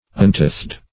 Untithed \Un*tithed"\, a. Not subjected tithes.